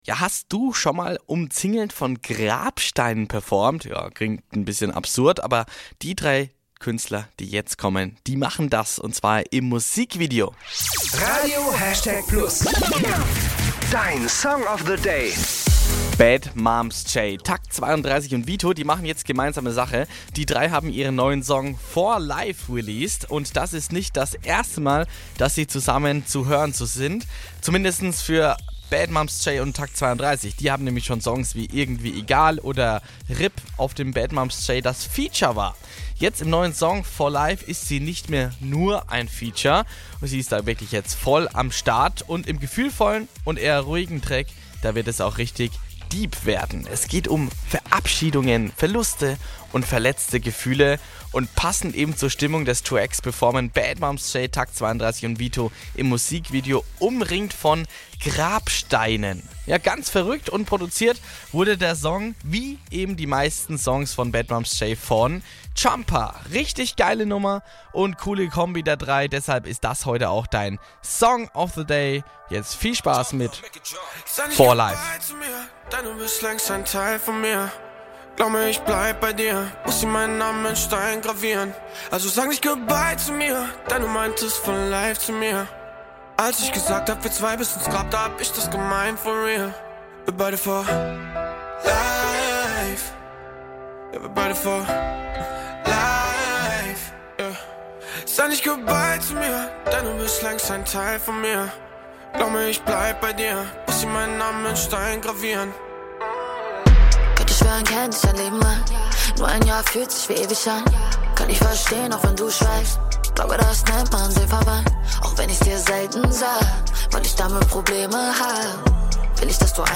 Im gefühlvollen und eher ruhigen Track wird es deep.